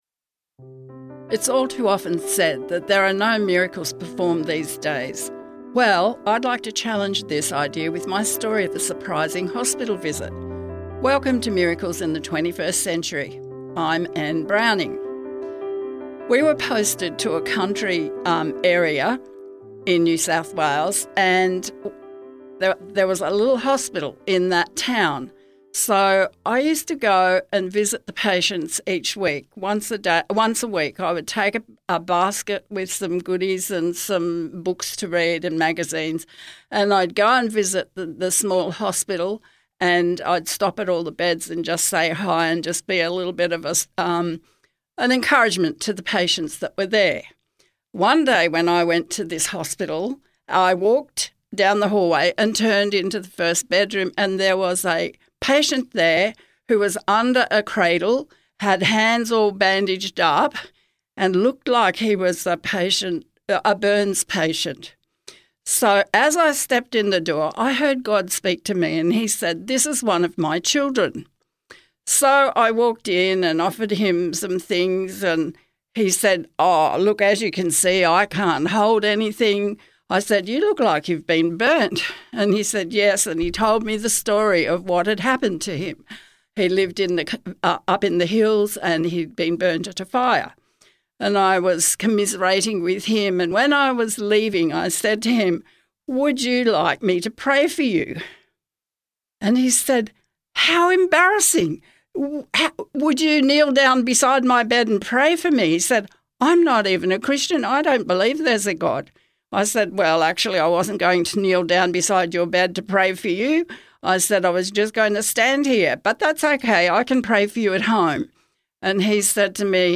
Music Credits: